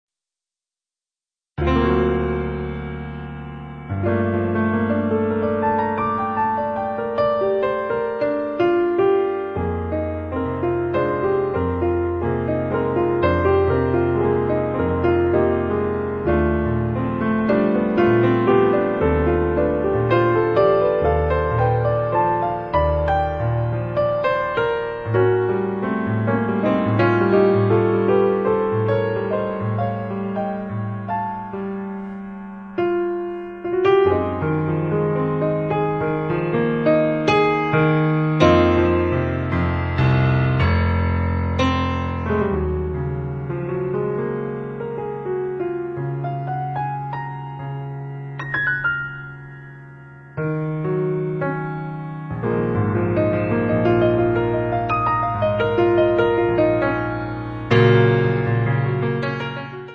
pianoforte, fender rhodes
sax tenore e soprano
rilette con felpato lirismo